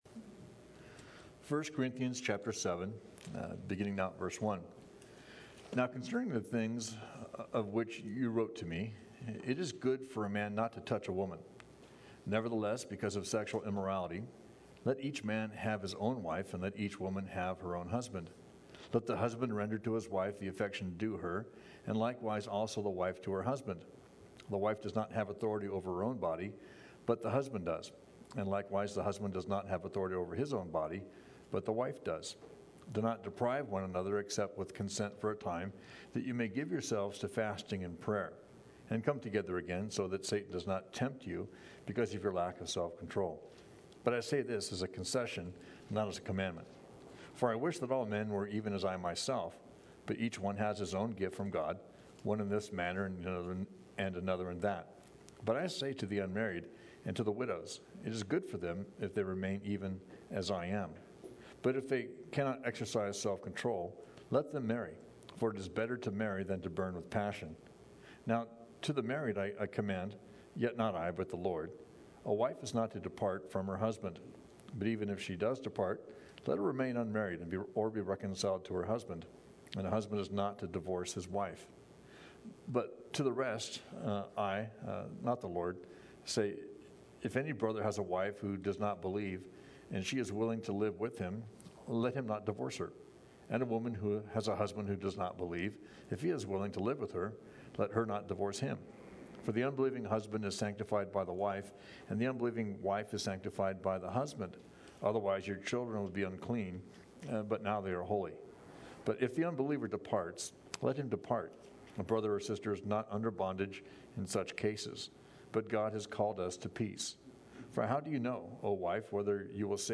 Thank’s for checking out our study in 1st Corinthians.